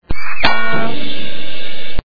The sound bytes heard on this page have quirks and are low quality.
CARTOON MOVEMENT SOUND # 07 1.92